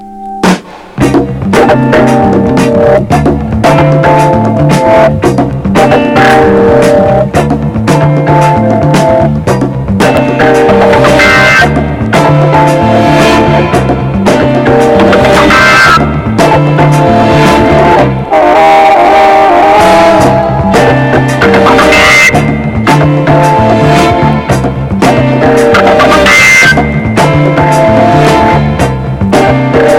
Groove psychédélique